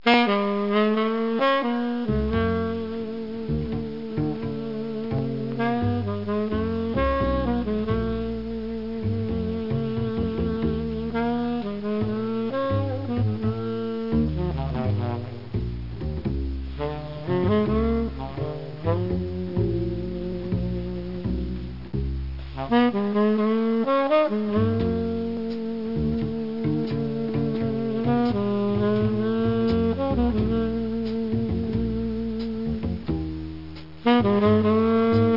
1 channel
sax.mp3